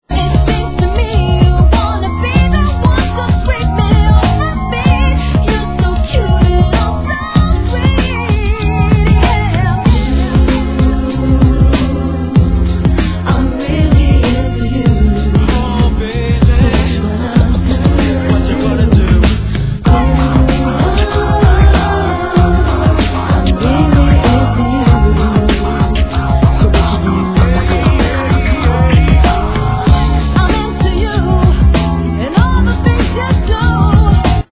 Tag       CLASICCS R&B